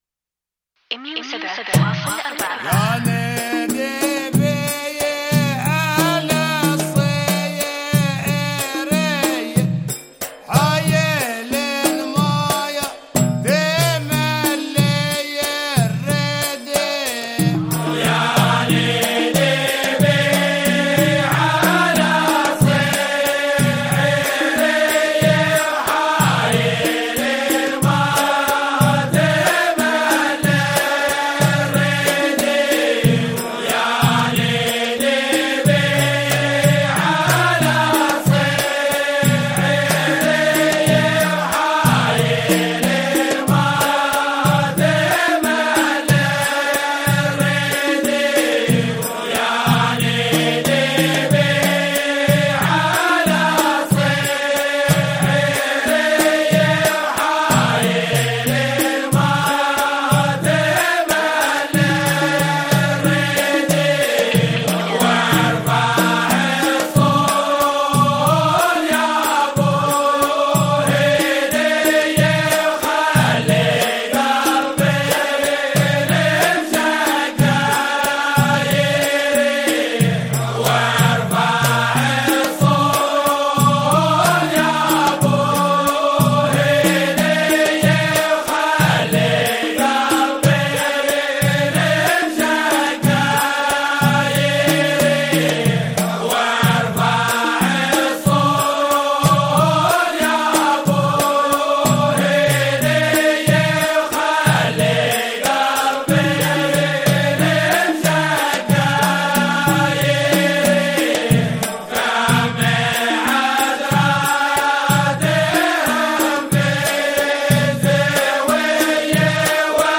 يا نديبي على - فرقة العيالة